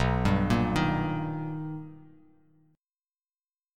B+M7 Chord
Listen to B+M7 strummed